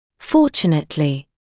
[‘fɔːʧənətlɪ][‘фоːчэнэтли]к счастью; счастливо